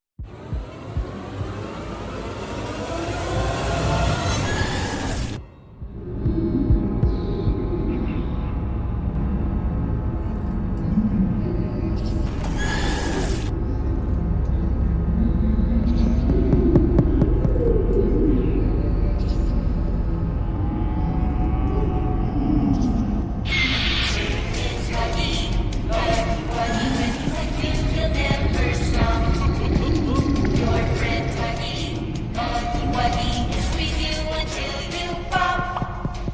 На этой странице собрана коллекция звуков и голосовых фраз Haggy Wagy.
Страшные звуки Хаги Ваги